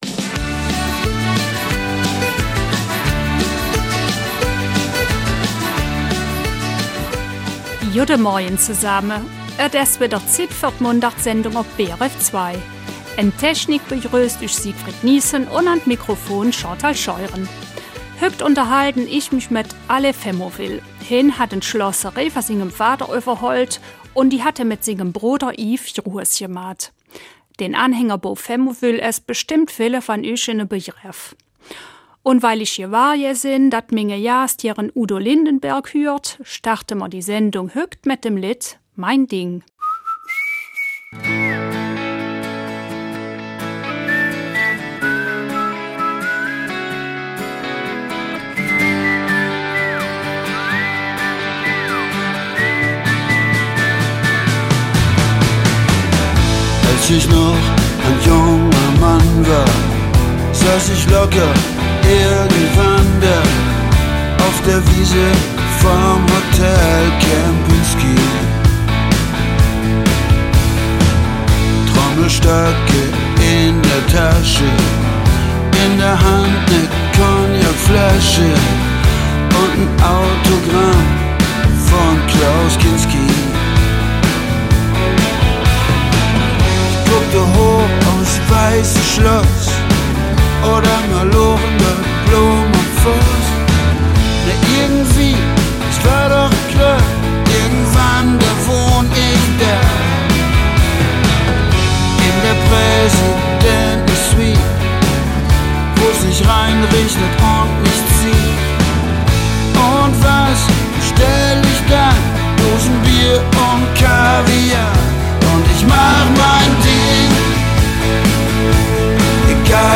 Eifeler Mundart